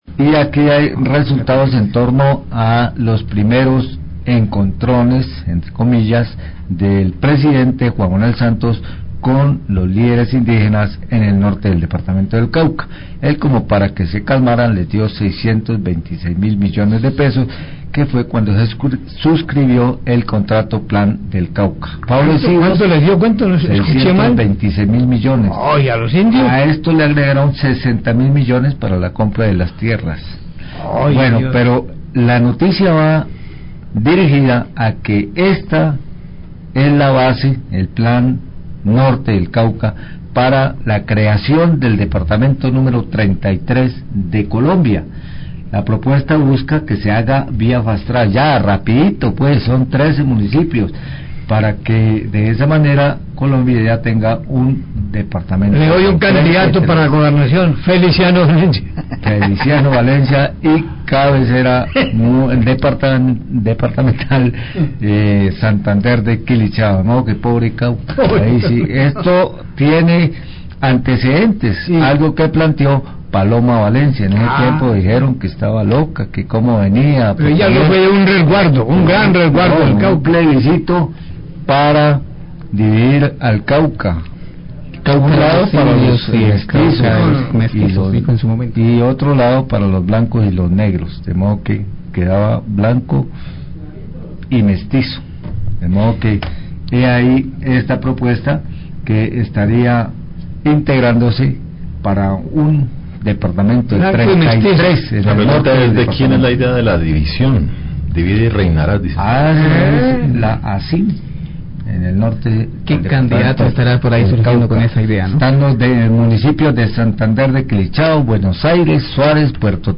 Radio
Periodistas comentan sobre la propuesta de creación de un nuevo departamento de Colombia que congregue a los municipios nortecaucanos con cabecera municipal en Santander de Quilichao.